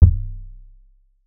CDK Wavy Kick.wav